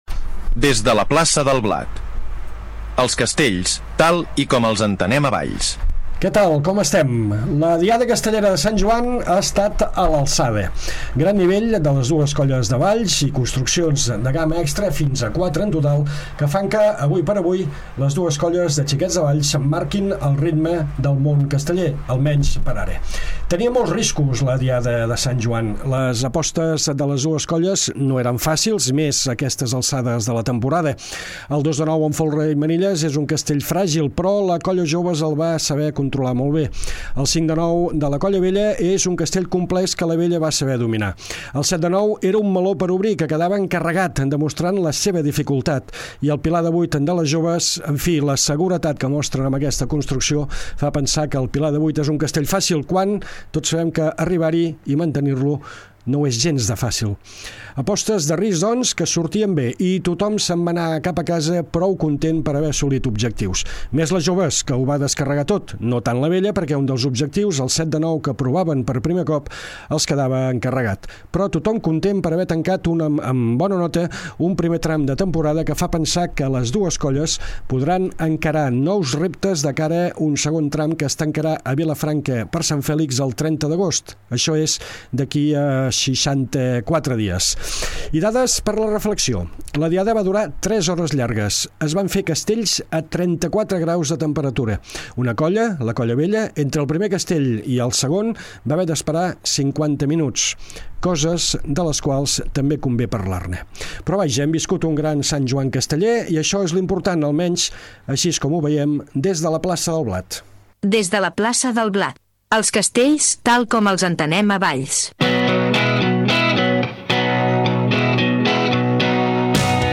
Segon programa de Des de la Plaça del Blat, el programa que explica els castells tal com els entenem a Valls. Tertúlia d’anàlisi de la diada de Sant Joan